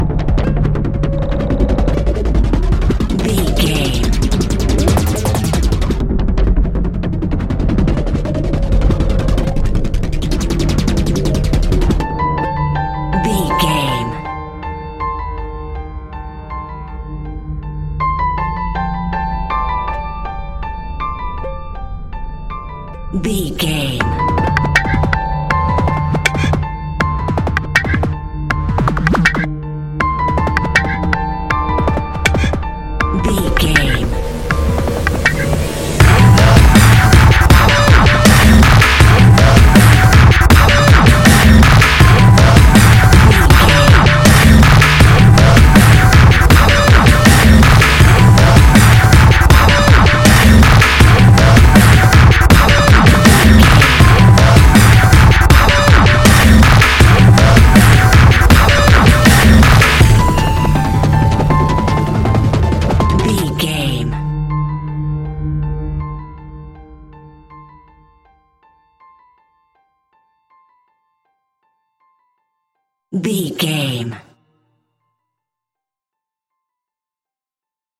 In-crescendo
Aeolian/Minor
Fast
aggressive
dark
hypnotic
industrial
heavy
drum machine
synthesiser
piano
breakbeat
energetic
synth leads
synth bass